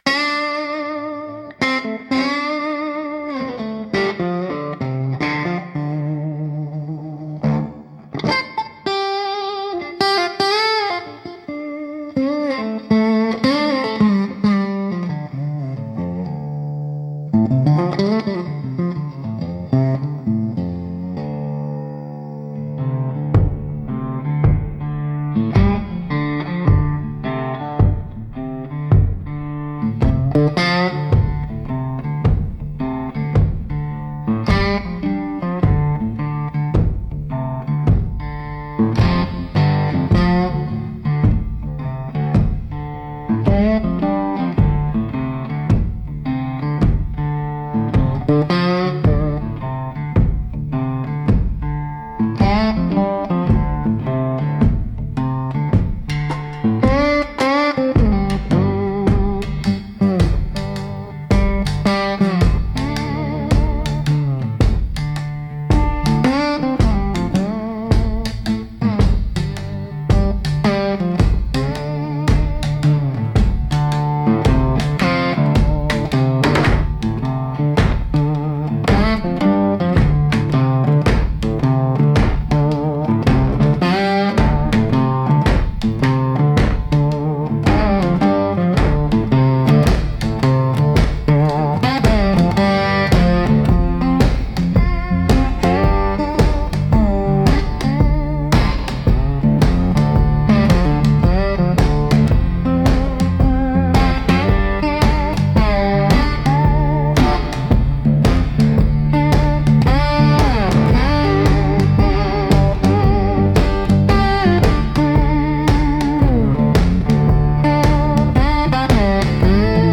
Instrumental - Rust & Whiskey 2.46